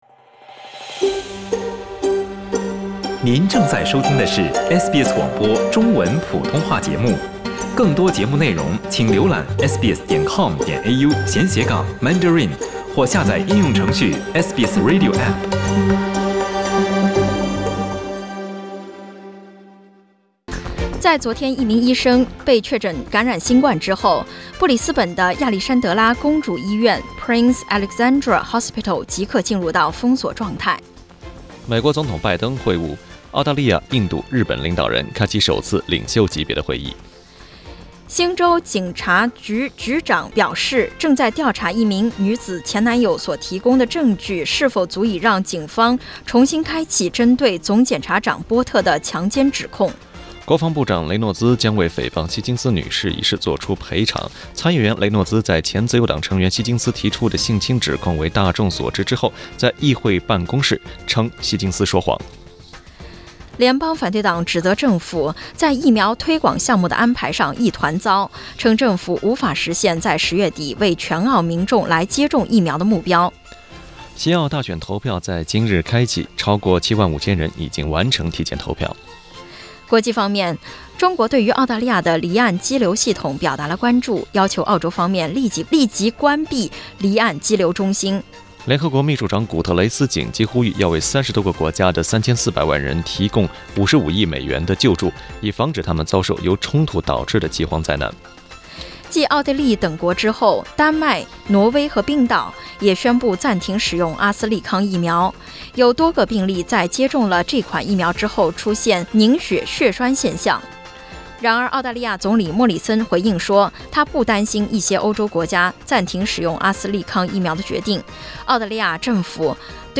SBS早新聞（3月13日）